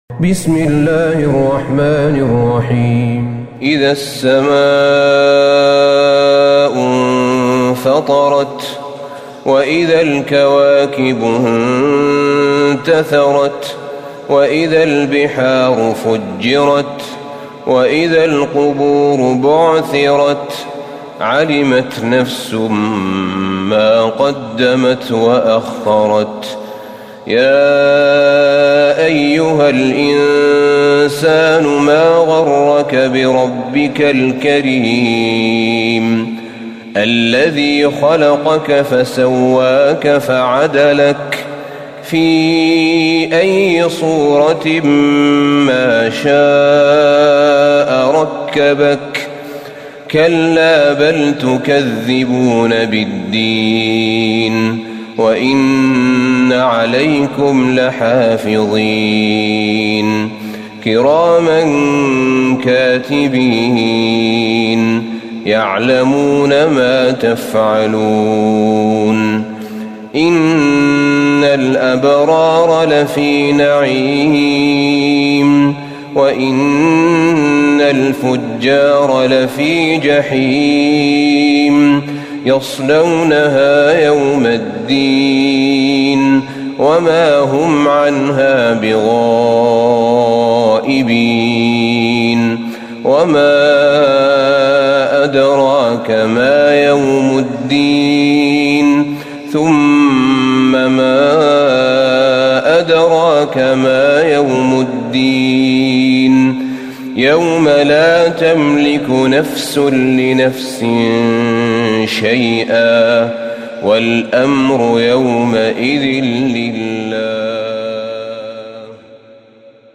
سورة الانفطار Surat Al-Infitar > مصحف الشيخ أحمد بن طالب بن حميد من الحرم النبوي > المصحف - تلاوات الحرمين